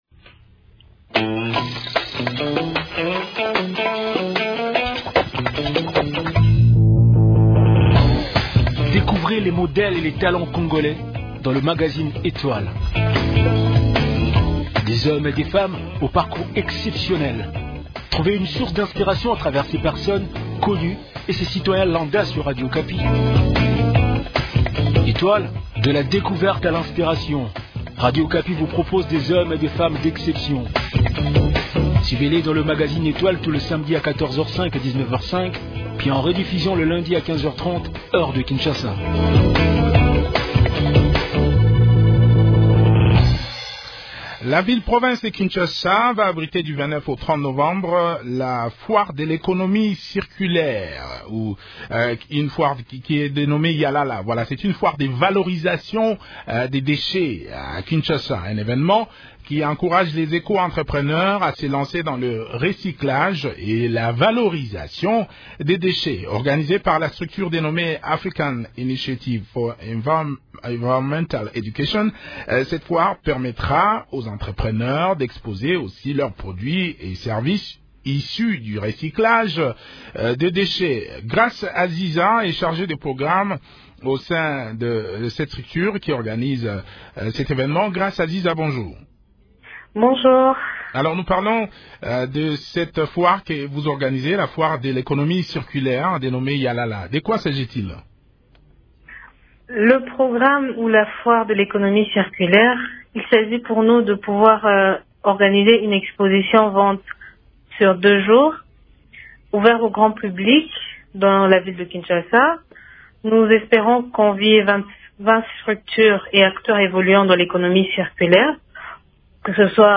Le point sur le préparatif de cette activité dans cet entretien